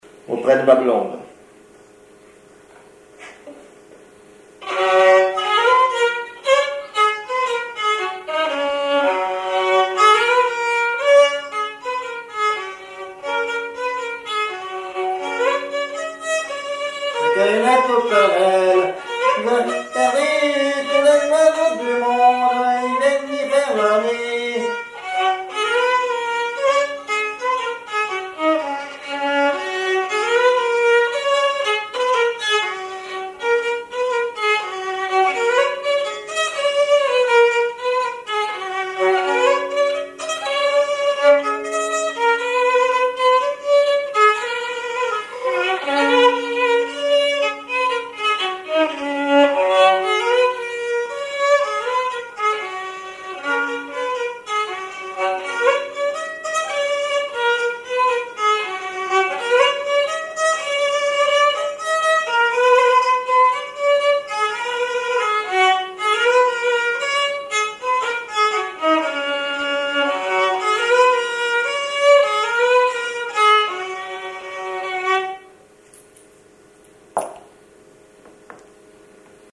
violoneux, violon
Pièce musicale inédite